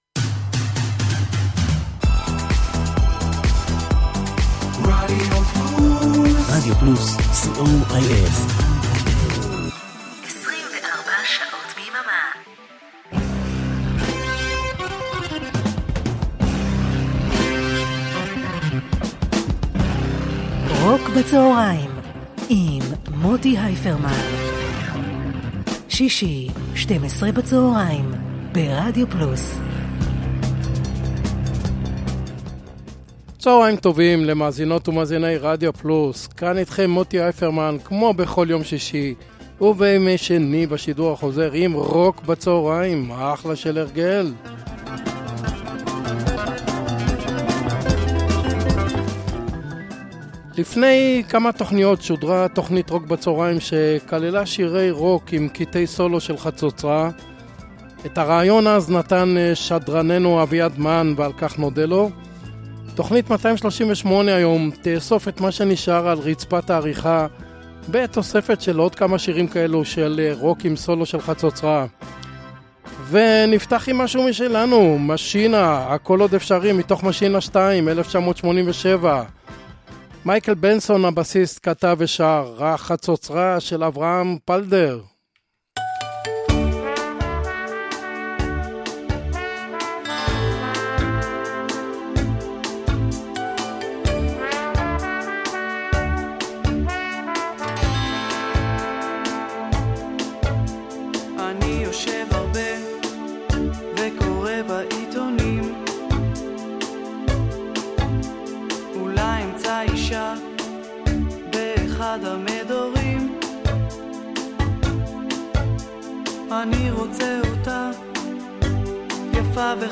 blues rock
classic rock
pop rock